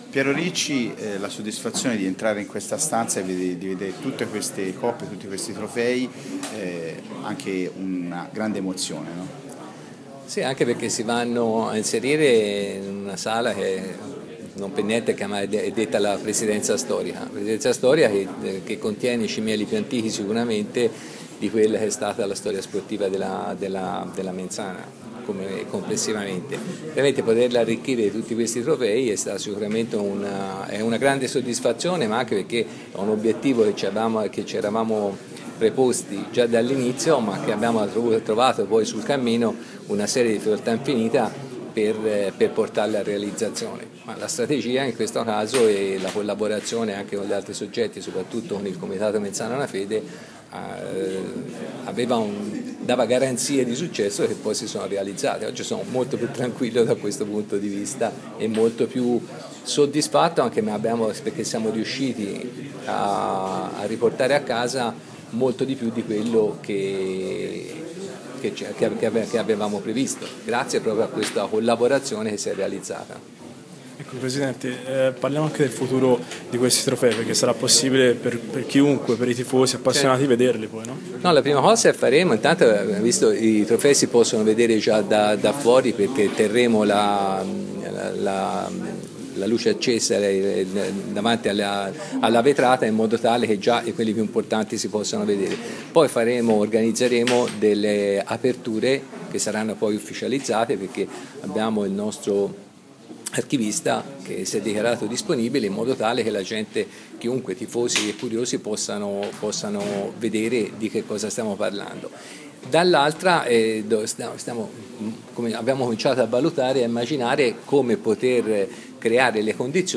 Si è svolta questa mattina presso la Presidenza Storica della Polisportiva la conferenza stampa relativa alla riacquisizione dei trofei della vecchia Mens Sana Basket.